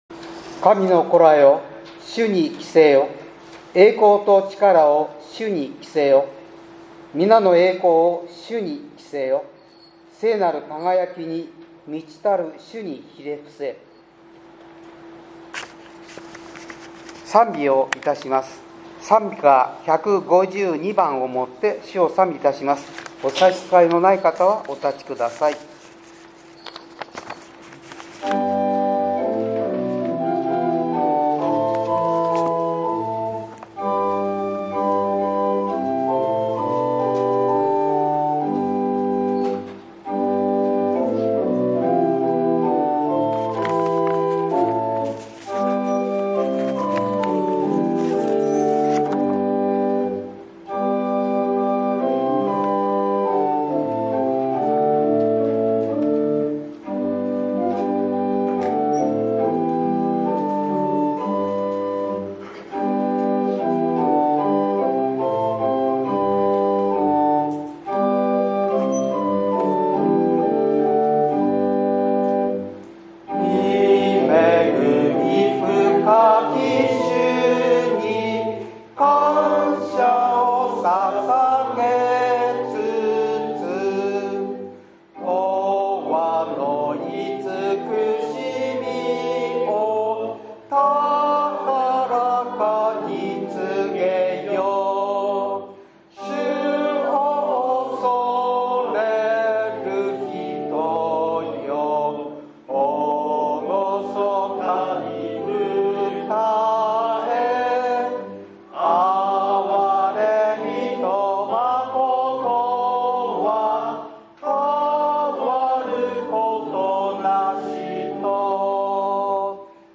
８月１７日（日）主日礼拝